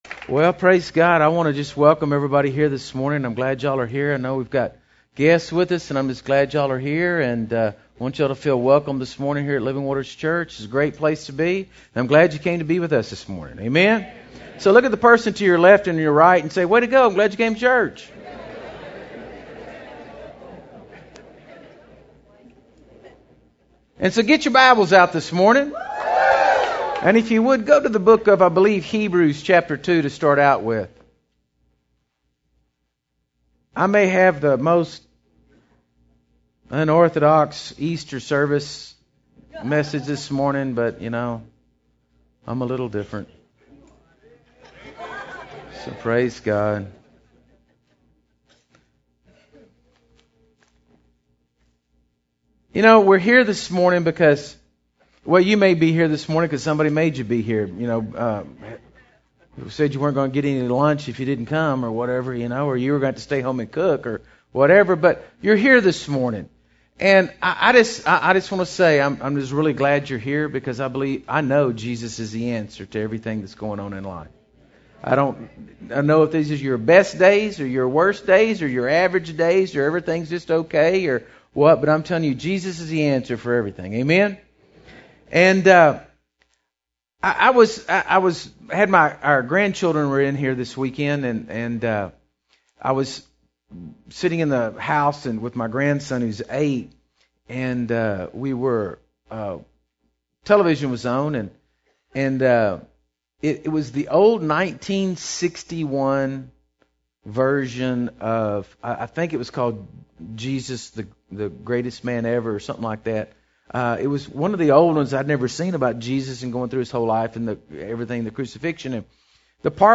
Sunday Services March 2013 Downloads To download right click on the message and select (save target as) or (save link as) 3-31-13 Easter 3-24-13 Passover 3-10-13 Selective Grace - Part 2 3-3-13 Selective Grace
Sunday_easter_3-31-13.mp3